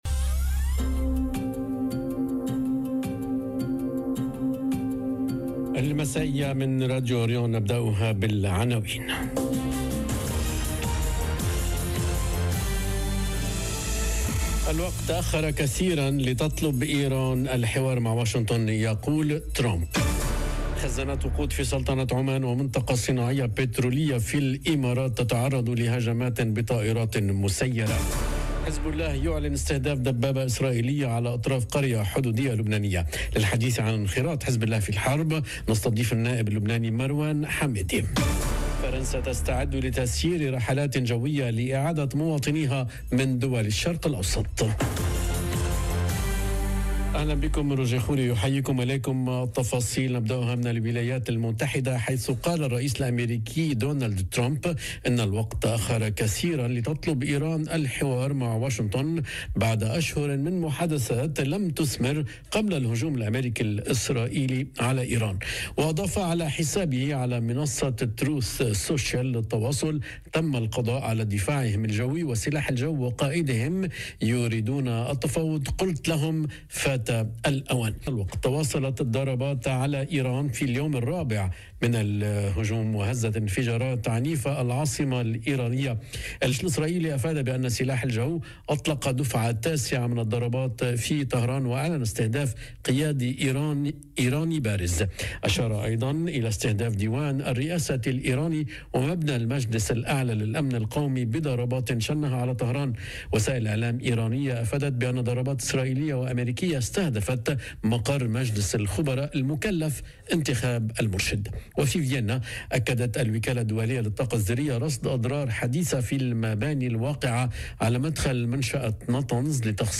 نشرة أخبار المساء